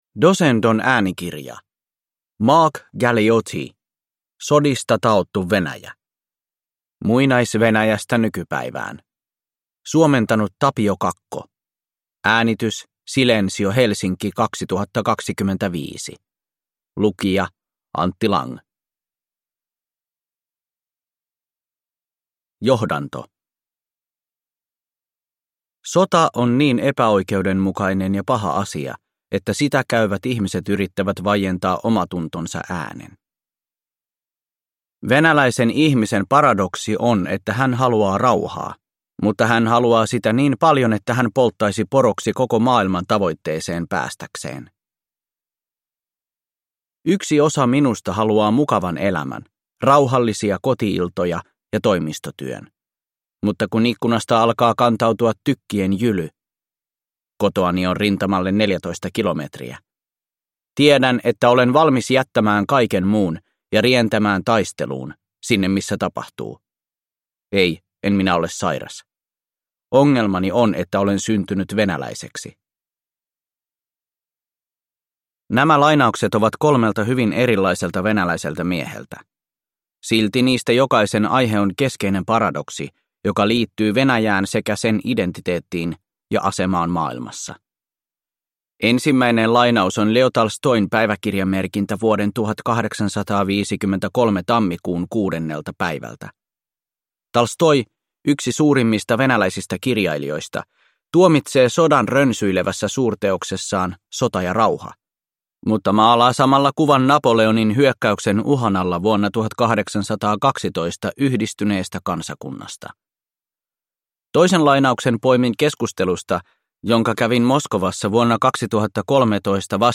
Sodista taottu Venäjä (ljudbok) av Mark Galeotti